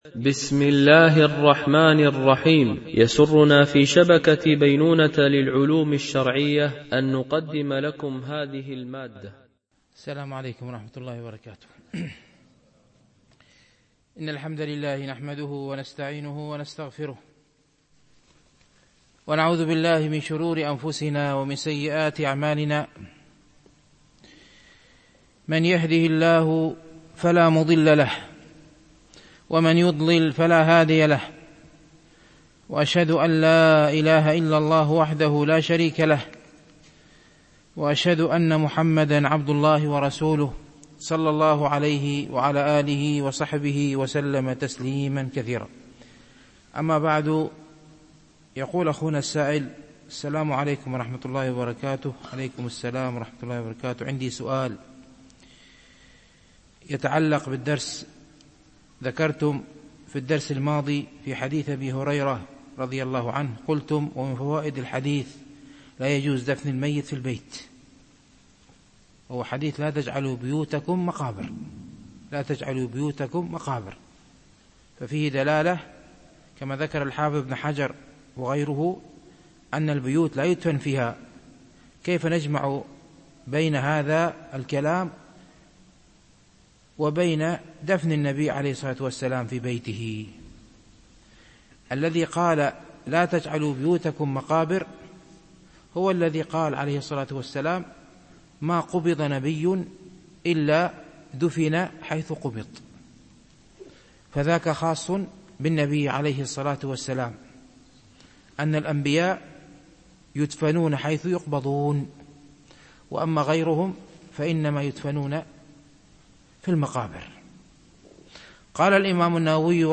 شرح رياض الصالحين – الدرس 267 ( الحديث 1030 – 1031 )